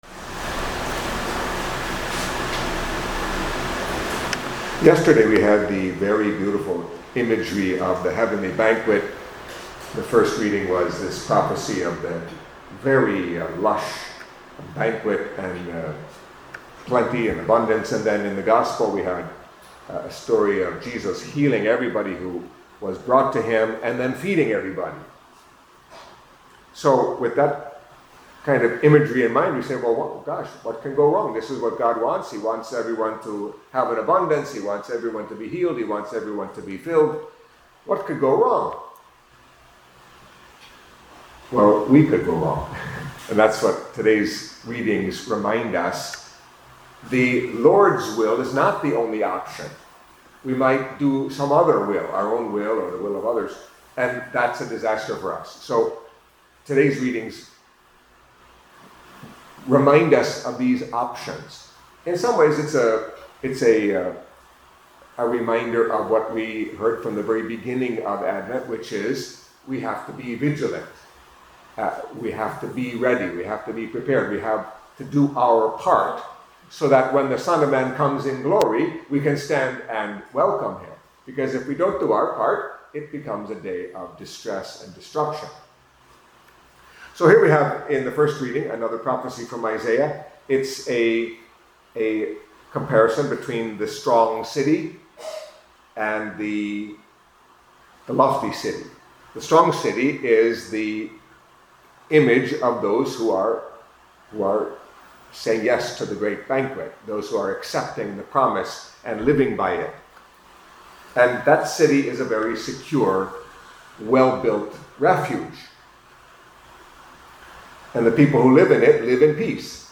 Catholic Mass homily for Thursday of the First Week of Advent